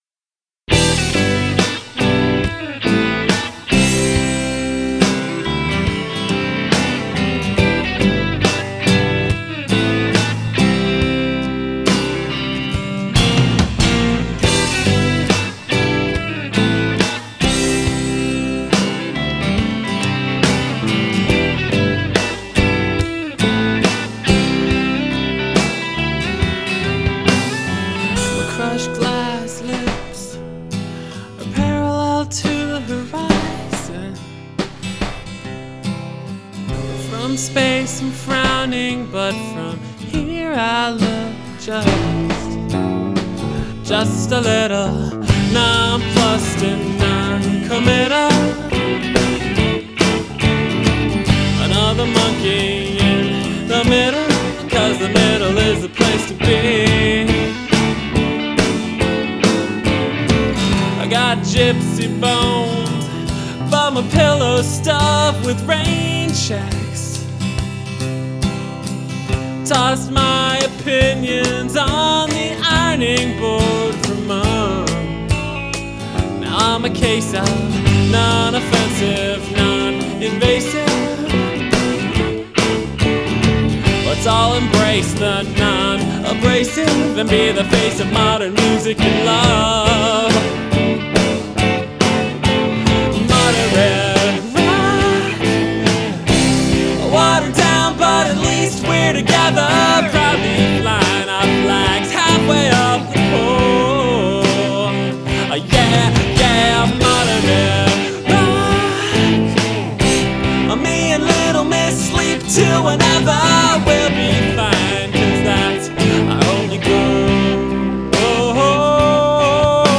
lead guitar